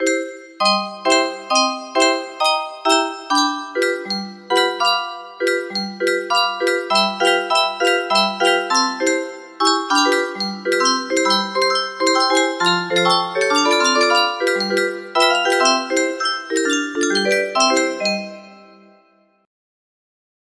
Jajcica music box melody
Grand Illusions 30 (F scale)